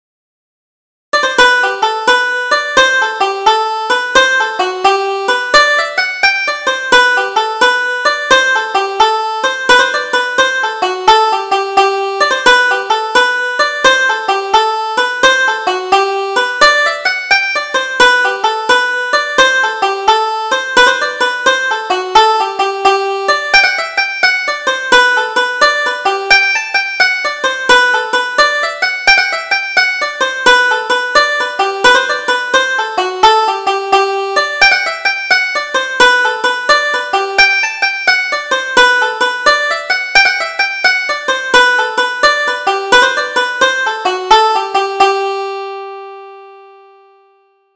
Double Jig: Boiled Goats' Milk